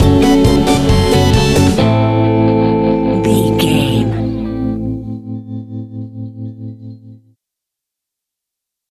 Aeolian/Minor
scary
ominous
eerie
piano
drums
bass guitar
synthesizer
spooky
horror music